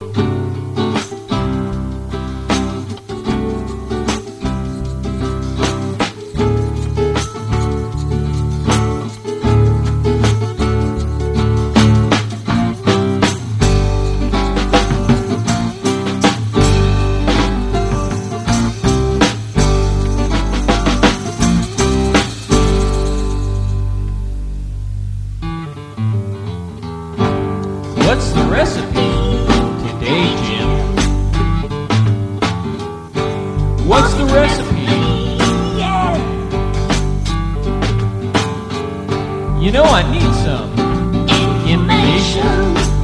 vocals and tambourine
backing vocals and shakers